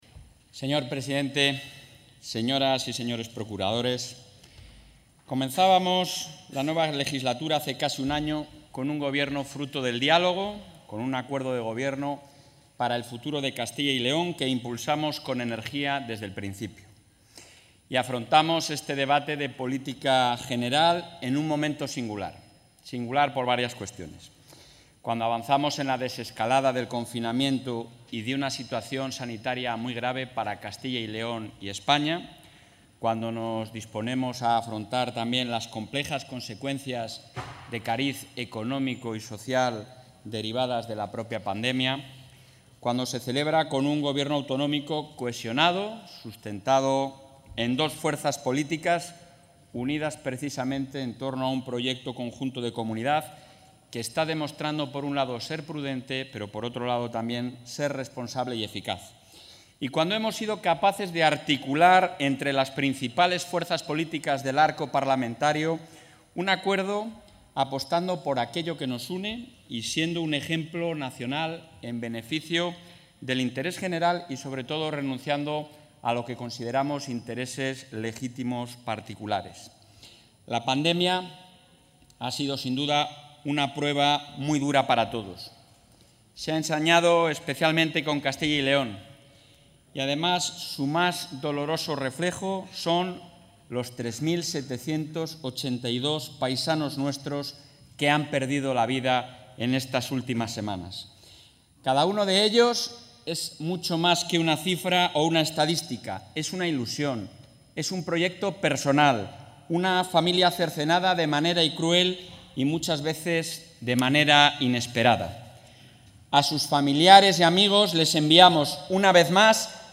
Intervención del presidente.
Durante su primer Debate sobre el Estado de la Comunidad, que se ha iniciado hoy en las Cortes de Castilla y León, el presidente del Ejecutivo autonómico, Alfonso Fernández Mañueco, ha abogado por transformar todo lo sucedido estos últimos meses en una oportunidad para la Comunidad, impulsando un amplio proceso de modernización en todos los ámbitos, al tiempo que ha invitado a todos los grupos parlamentarios a mantener el espíritu del Pacto por la Recuperación para poder consensuar los próximos presupuestos de la Comunidad.